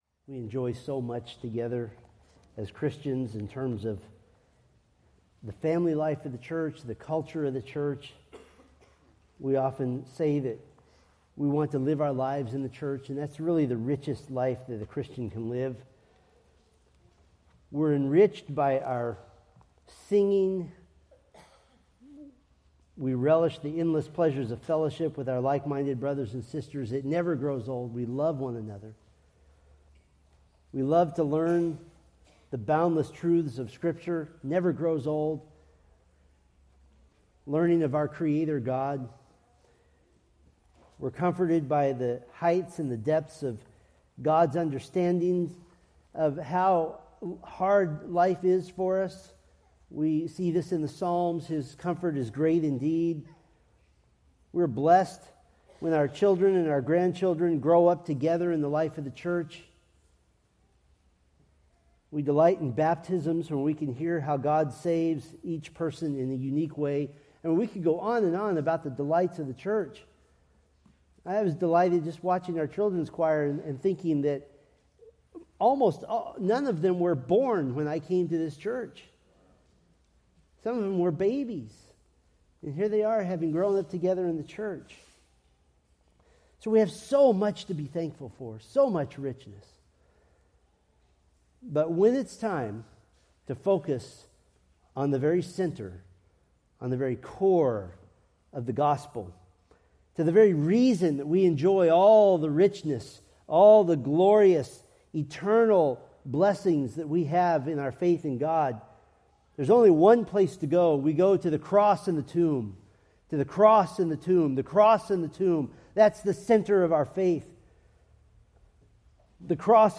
Preached April 13, 2025 from Mark 15:1-20